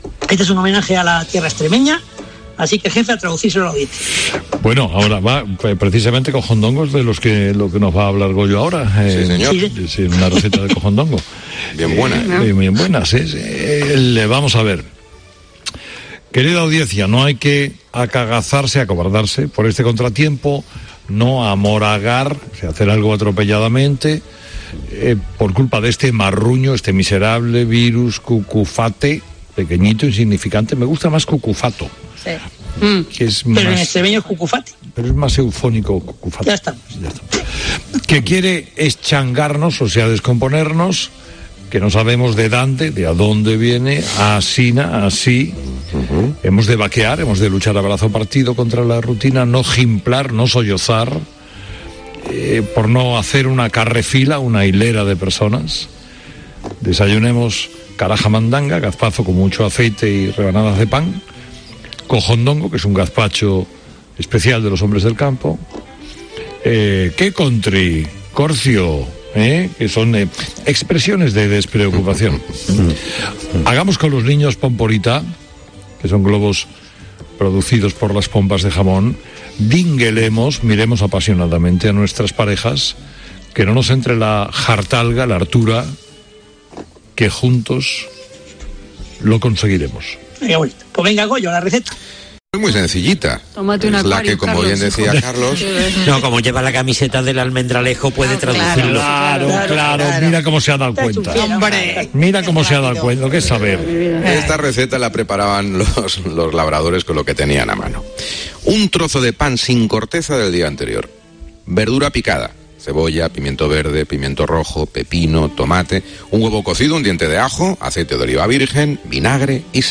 Herrera en Castúo